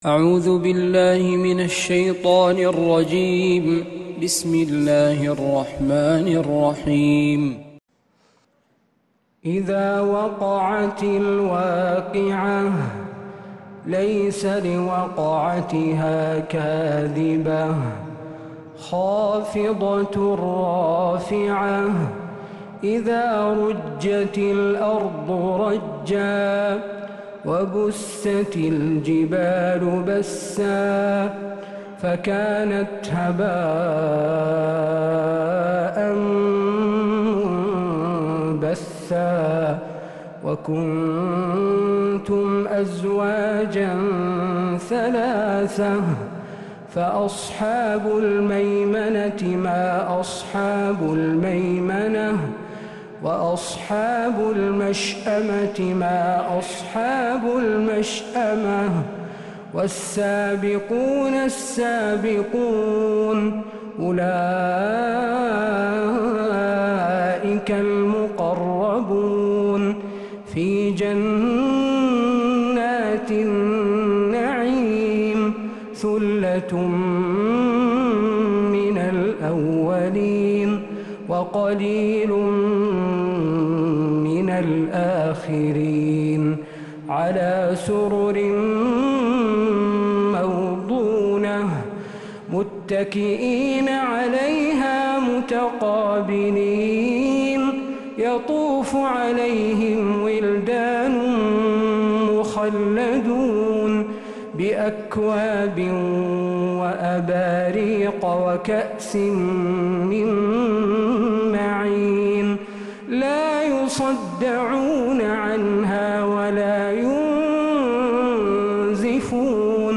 من فجريات الحرم النبوي